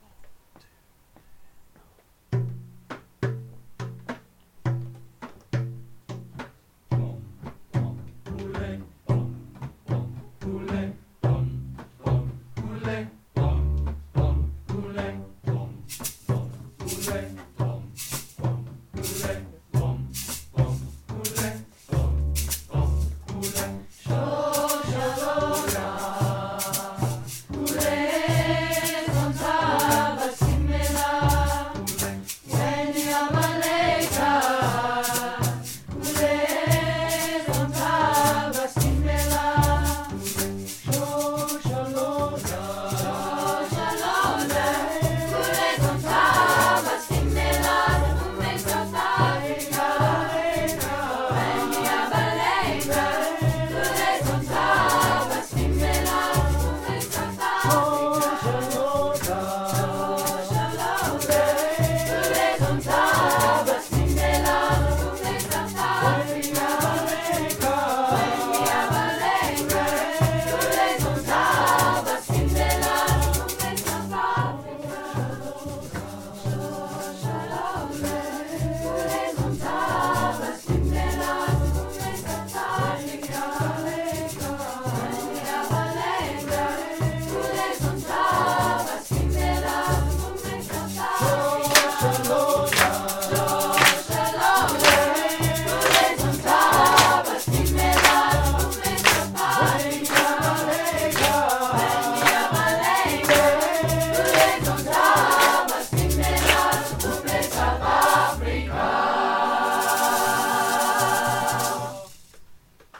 the students
Chamber, Choral & Orchestral Music
Chorus